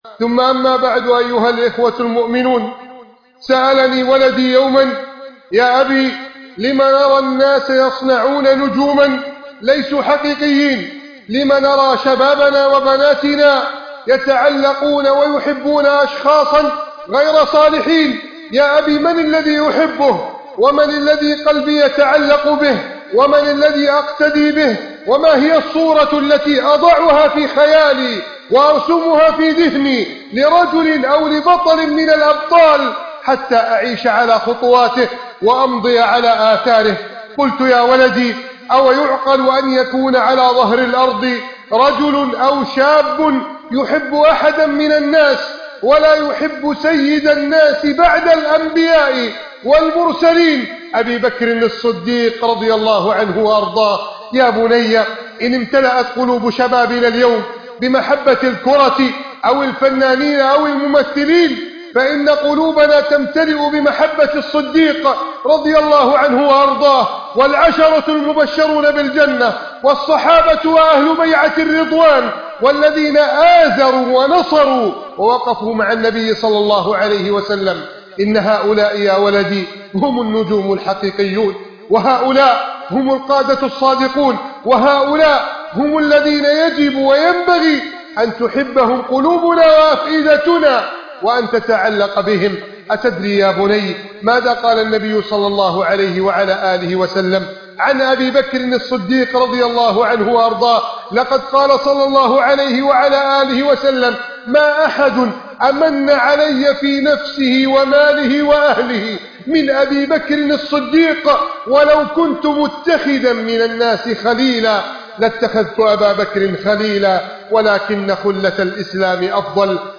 سر عظمة أبي بكر الصديق _ خطبة الجمعة المؤثرة